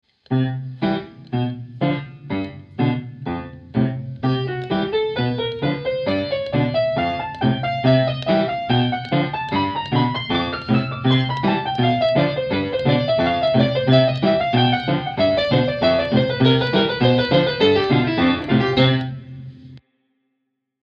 What kind of scene do these melodies suggest?
persecucion.mp3